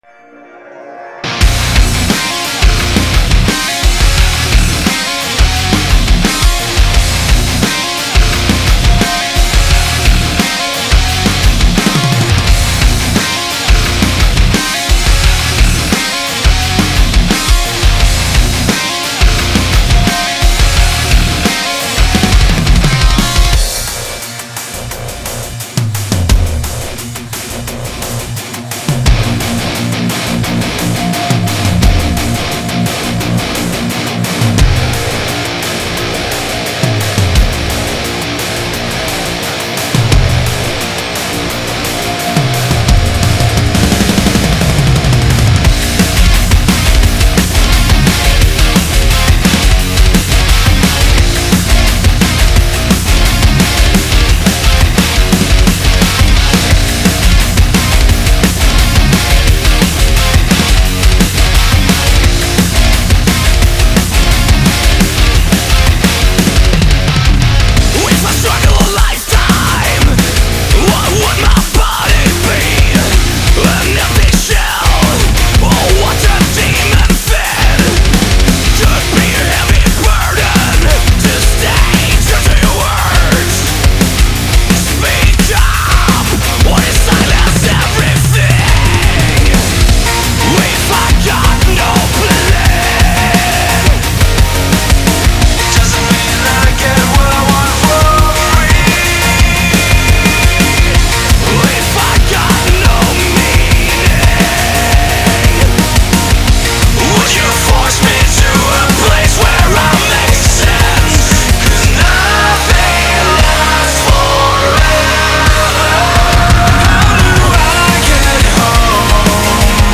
Рок музыка
рок песни